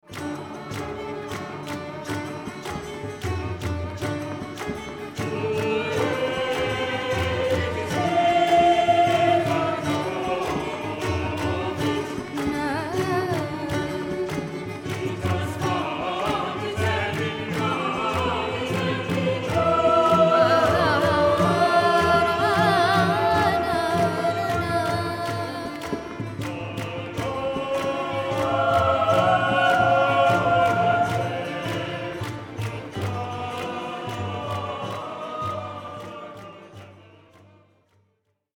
an intriguing fusion of Indian and Western music